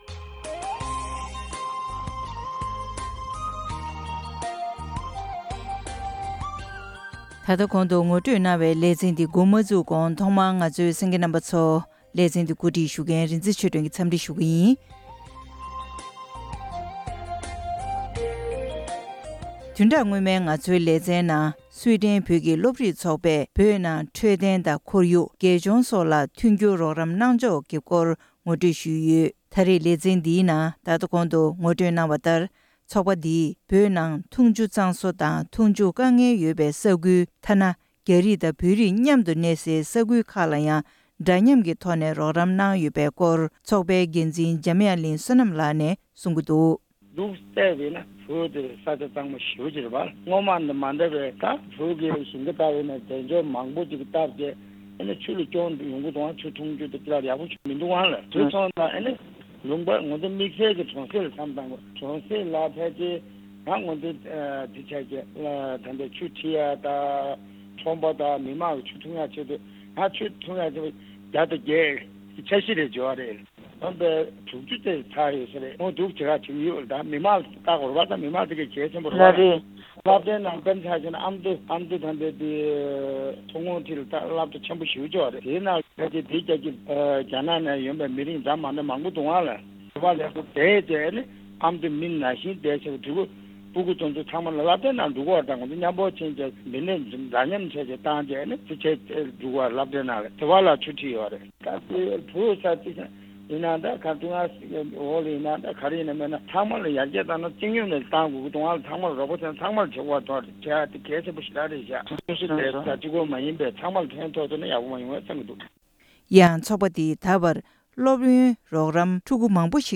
སྲུ་དྲེན་བོད་ཀྱི་སློབ་རིགས་ཚོགས་པ། ལེའུ་གསུམ་པ། སྒྲ་ལྡན་གསར་འགྱུར།